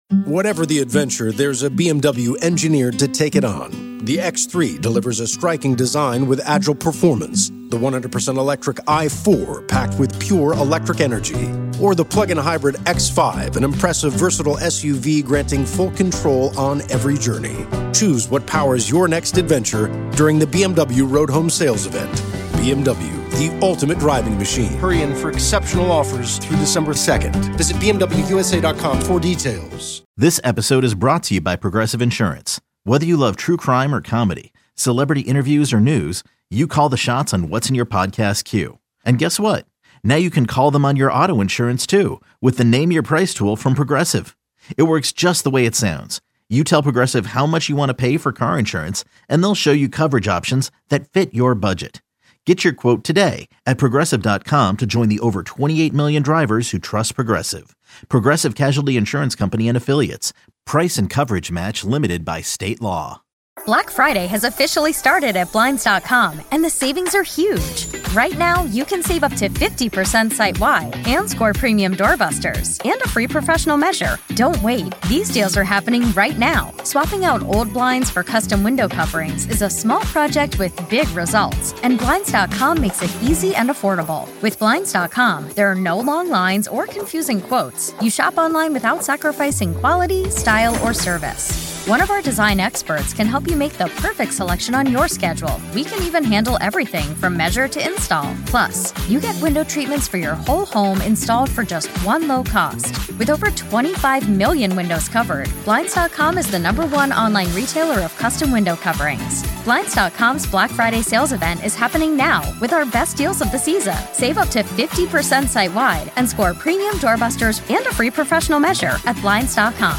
Comedian Interviews on Comedy 103.1